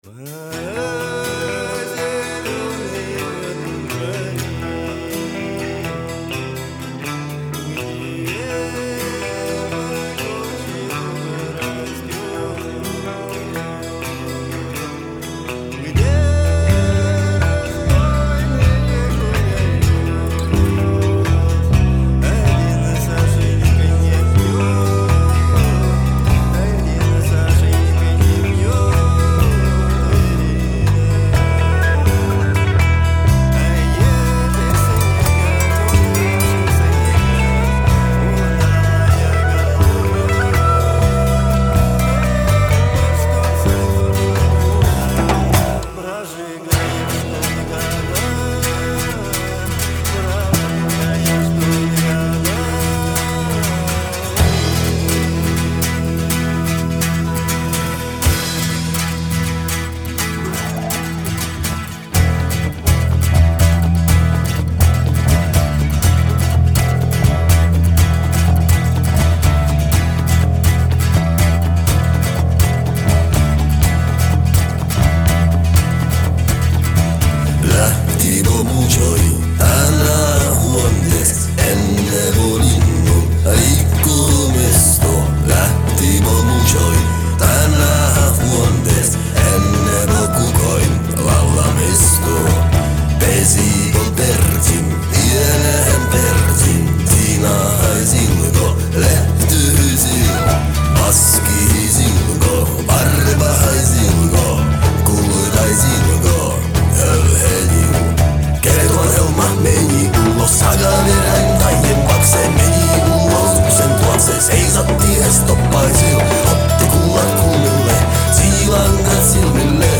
pajatandu, bass-gituaru, taganpajatandu
electrogituaru, gituaru, örineh
buzuki, huuligarmoniekku, garmoniekku, taganpajatandu
barabanat